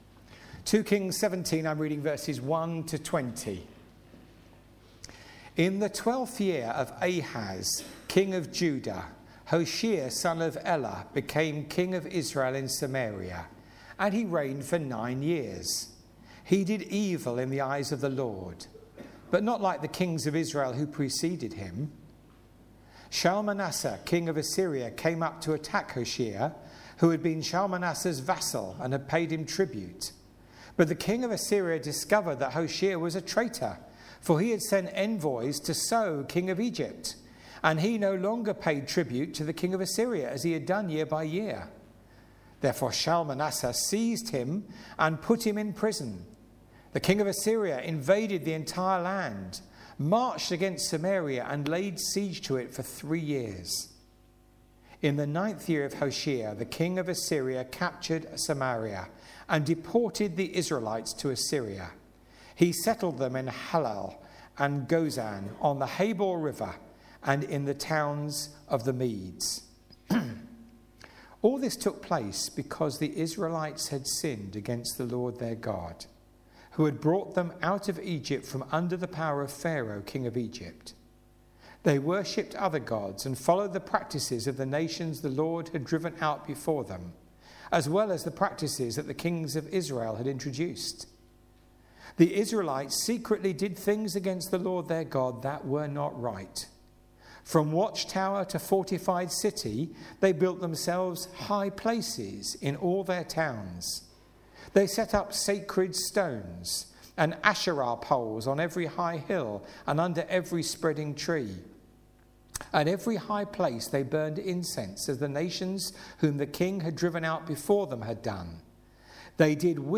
Media for Sunday Service on Sun 03rd Jan 2016 10:00
Theme: Sermon